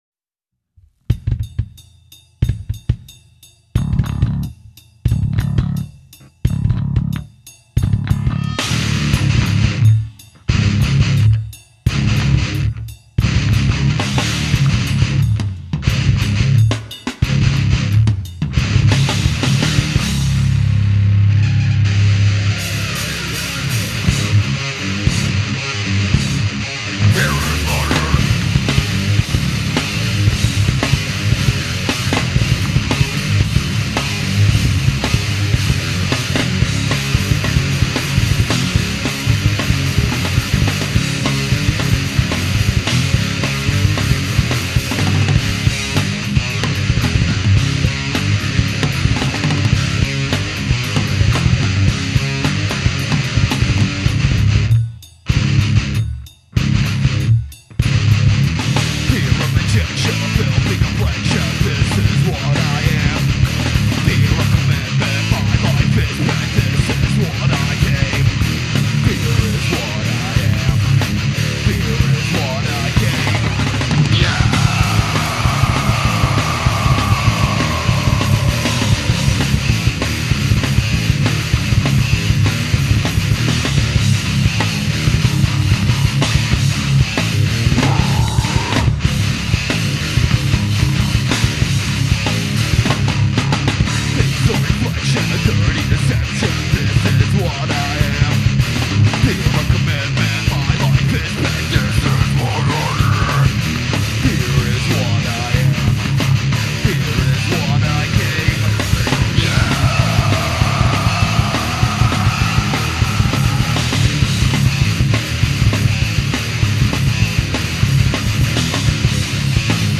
All MP3's on site contain edited tracks.